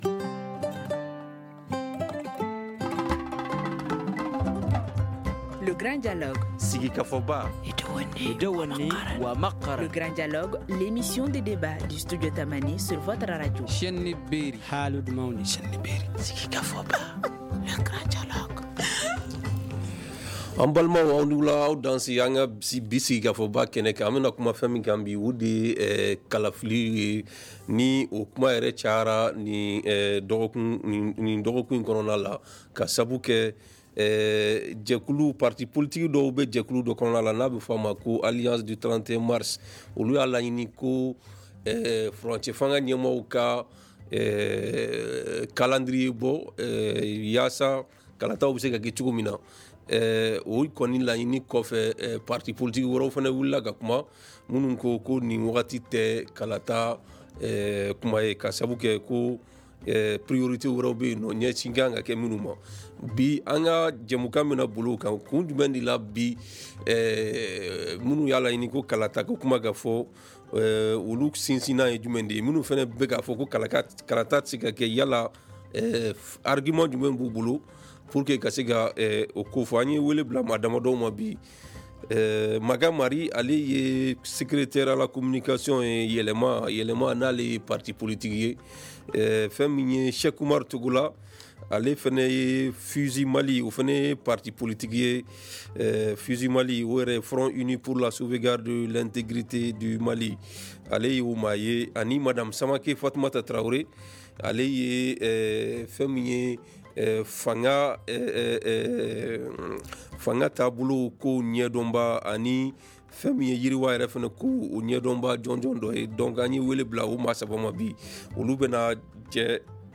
Polémique autour de la publication du chronogramme électoral - Studio Tamani - Informations, débats, magazines : toute l’actualité du Mali, en 5 langues